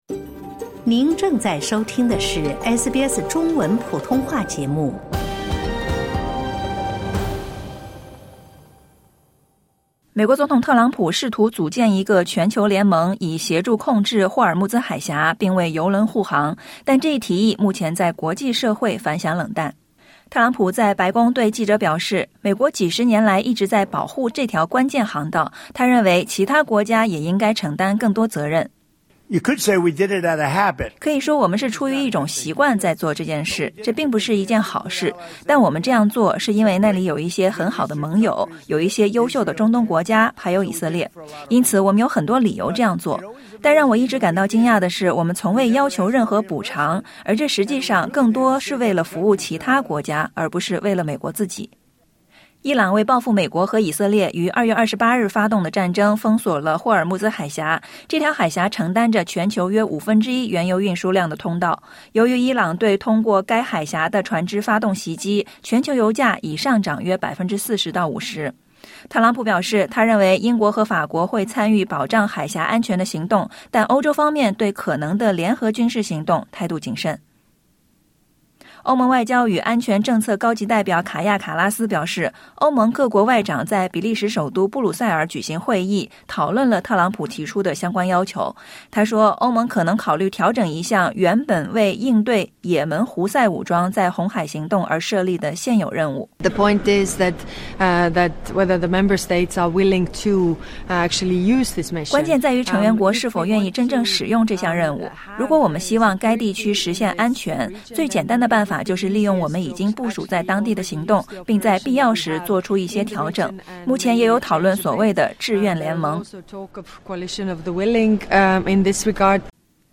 美国总统特朗普试图组建一个全球联盟，以协助控制霍尔木兹海峡（Strait of Hormuz）并为油轮护航，但这一提议目前在国际社会反响冷淡。点击音频收听报道。